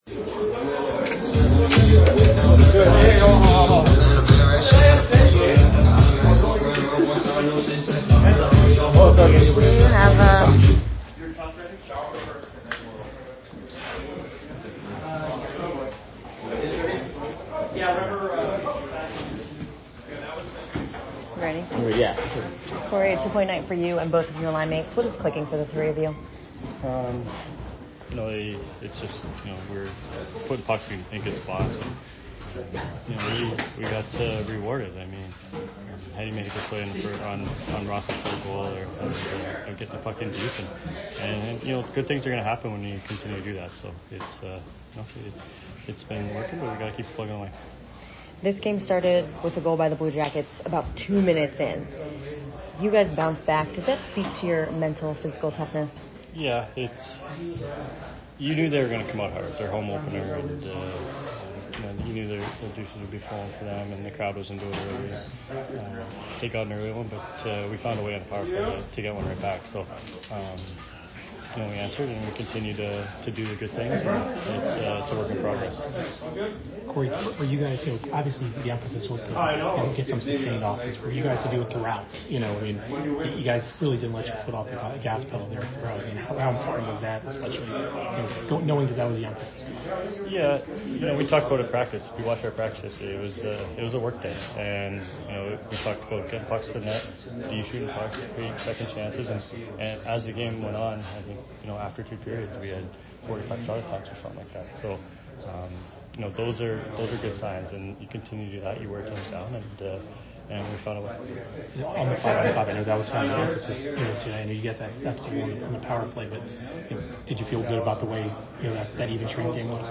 Corey Perry Post Game 10/14/22 @ CBJ